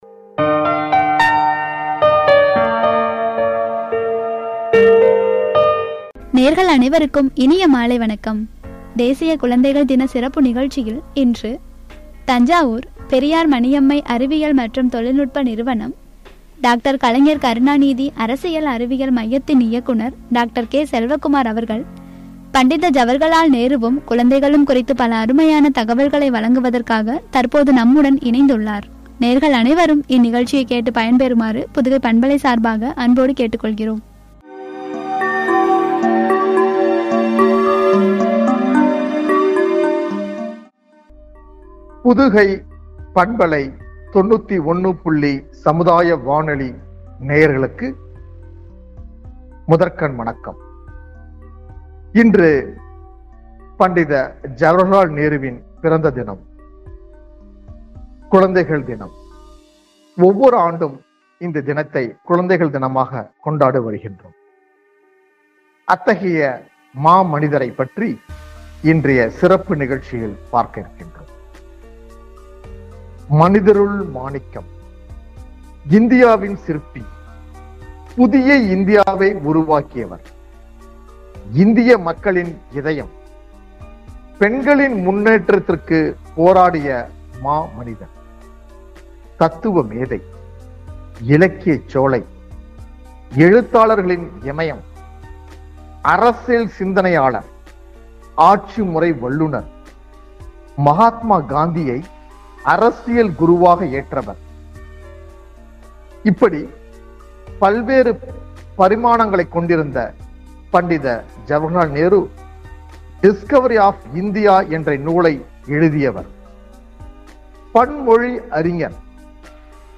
உரை